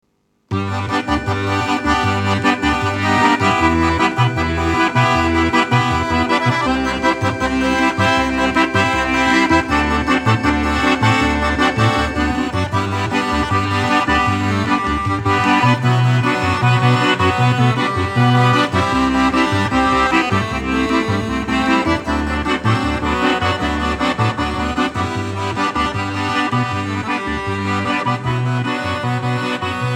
Recorded at Stebbing Recording Studios